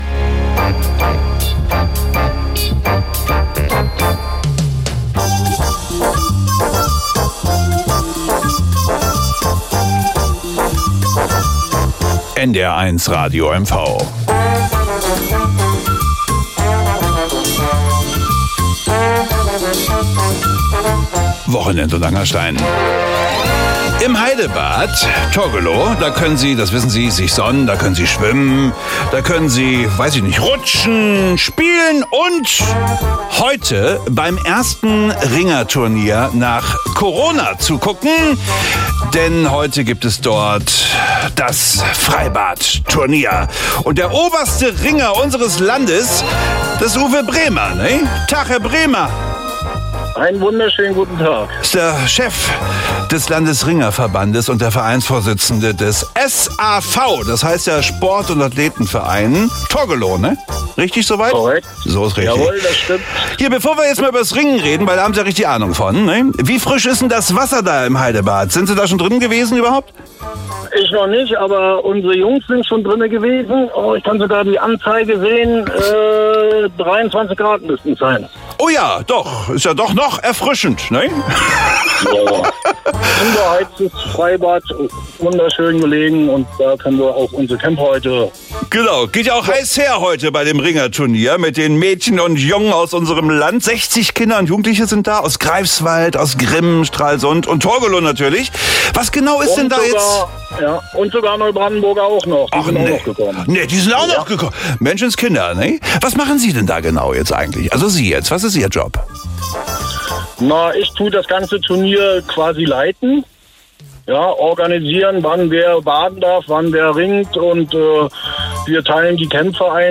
Radiointerview